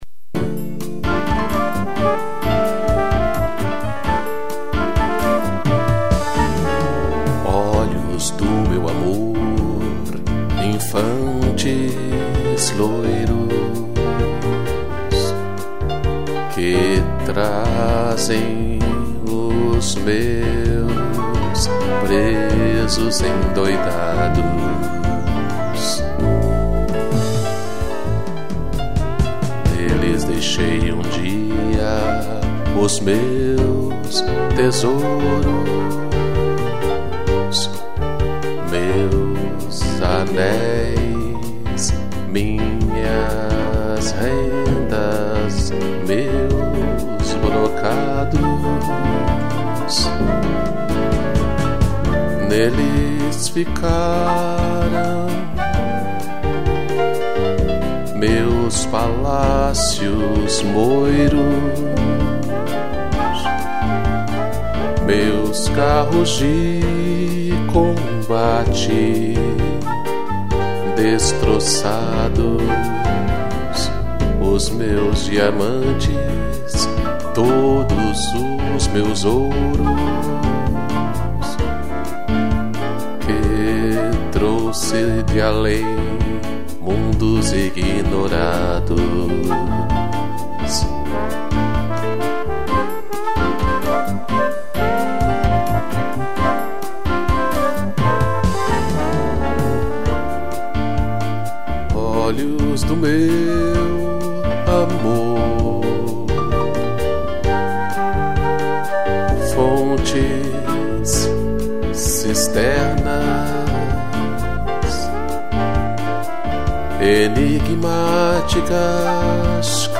piano, trombone e flauta